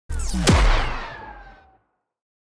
Download Free Sci-Fi Weapons Sound Effects | Gfx Sounds
This collection features an extensive selection of high-quality, Sci-Fi Weapons Sound Effects designed to bring your Futuristic Weapons to life.
Sci-fi-weapon-plasma-sniper-rifle-single-shooting-4.mp3